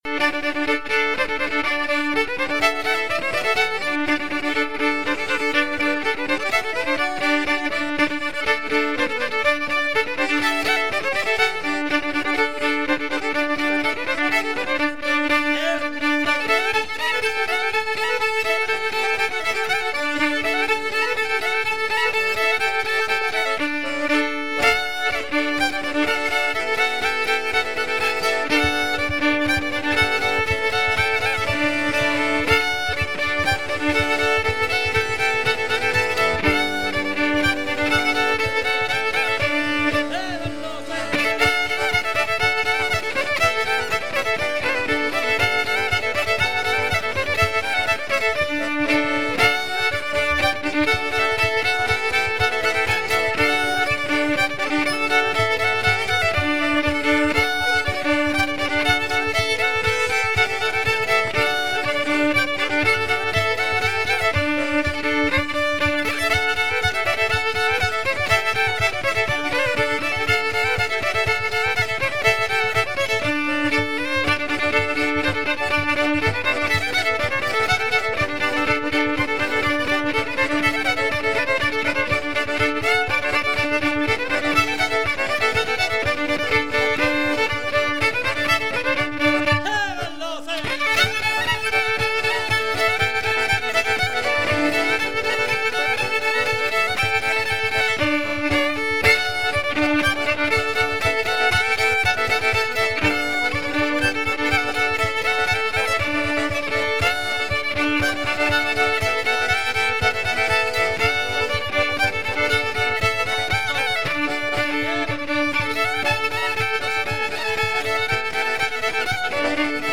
zpzpMusique Trad en Poitou
Bal aux Roches-Prémarie, salle du Clos des Roches